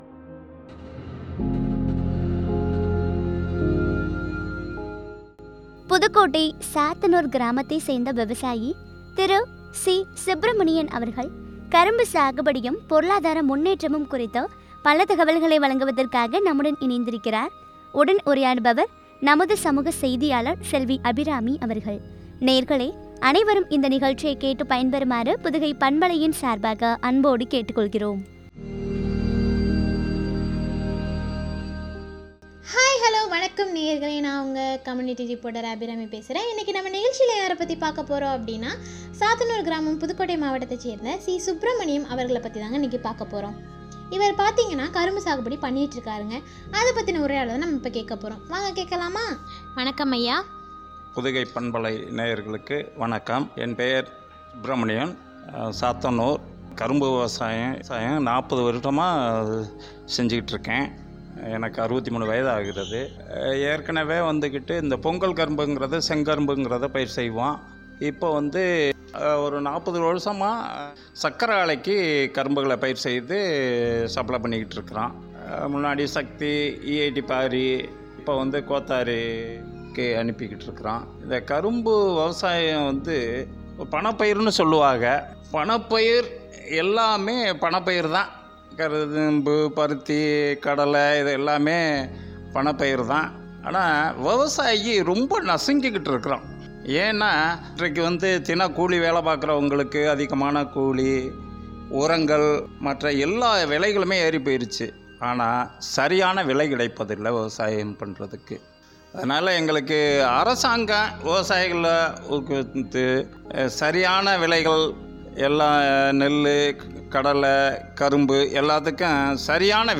கரும்பு சாகுபடியும் என்ற தலைப்பில் வழங்கிய உரையாடல்.